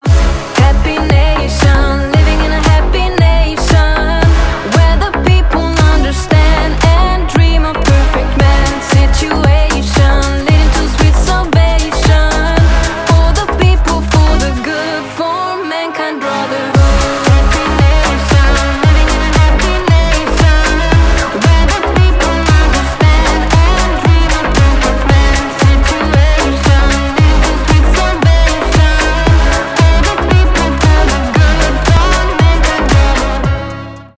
retromix
танцевальные